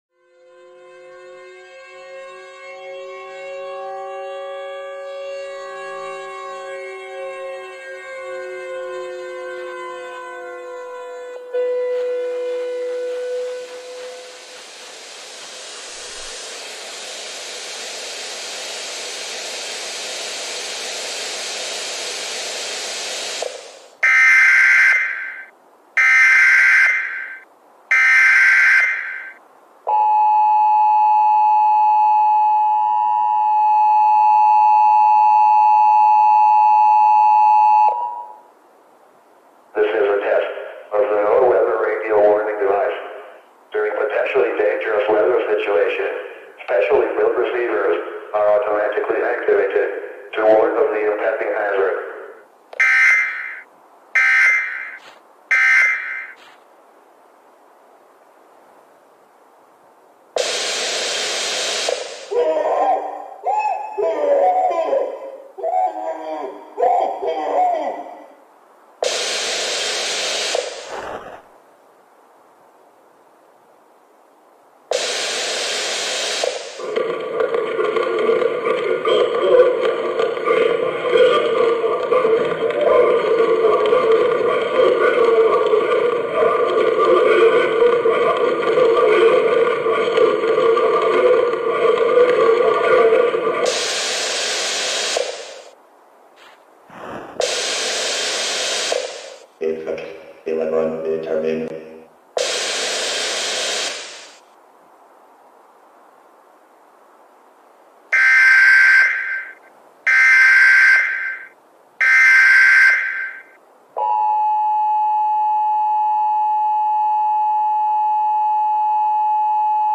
Sounds from the game Siren Head
Siren's Voice - Option 2 (long)800.24 kB128kB71
On this page you can listen to sounds from the game siren head.